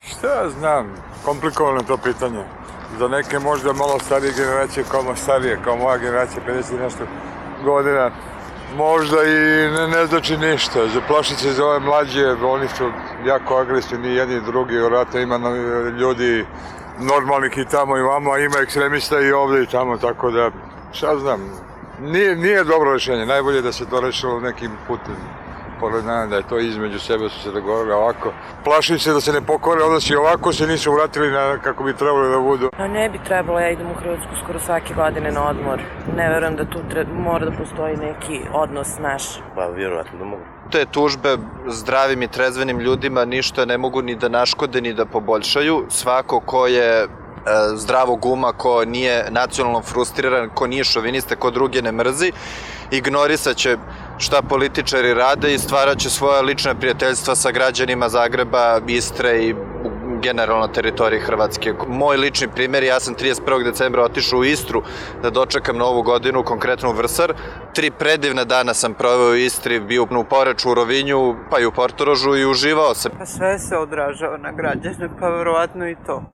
Anketa - Beograđani o tužbi